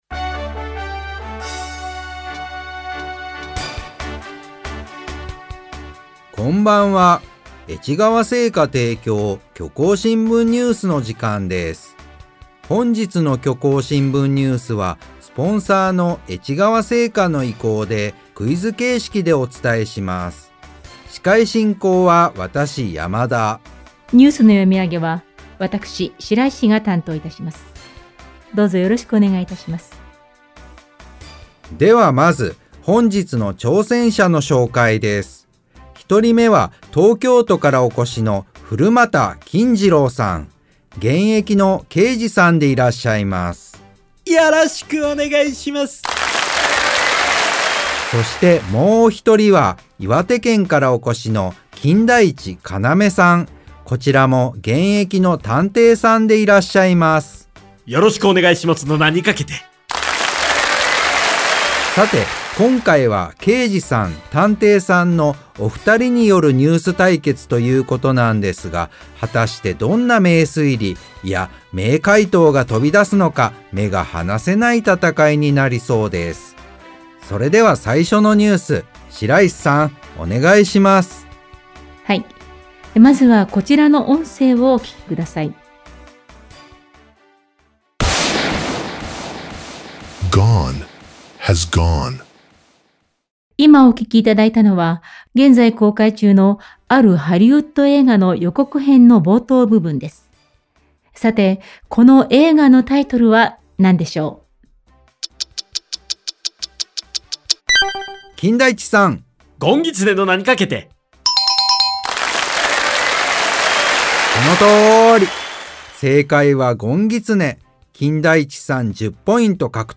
この番組は、他の新聞メディアにはない独自の取材網を持っている虚構新聞社がお届けする音声ニュース番組「虚構新聞ニュース」です。